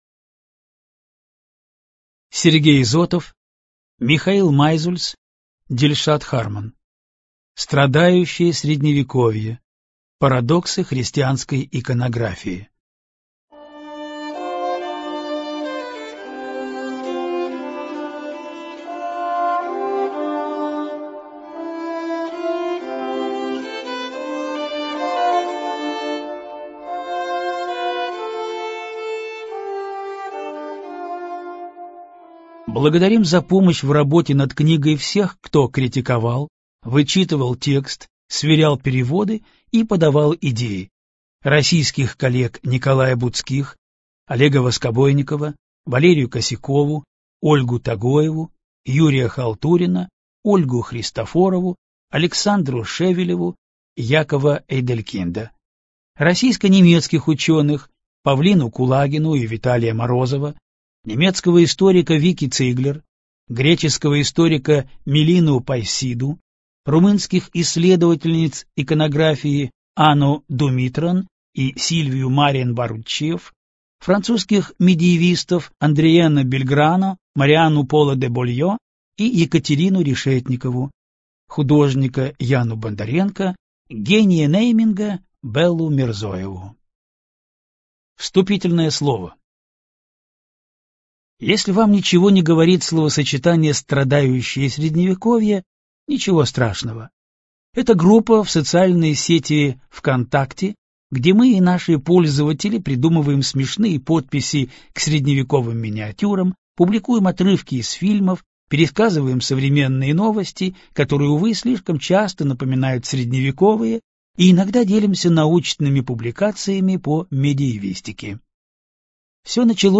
ЖанрКультура и искусство